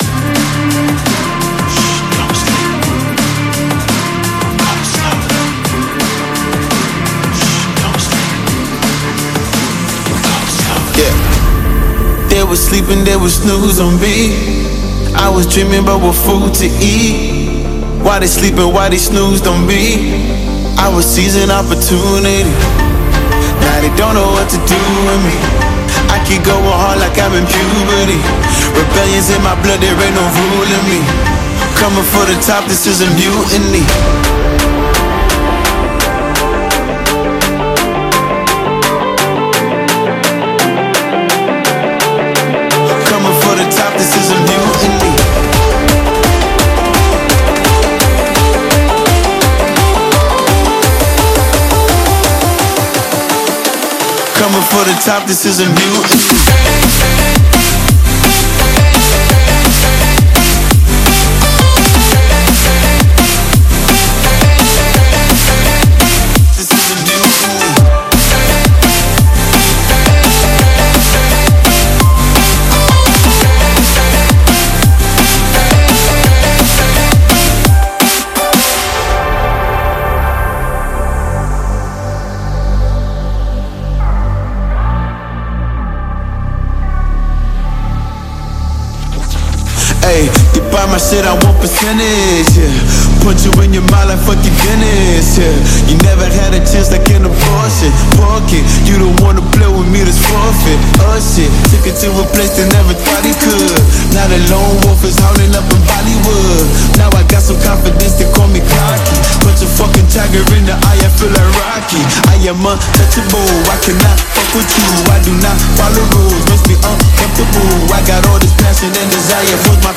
Drum & bass, Suspense, Elegant, Euphoric & Mysterious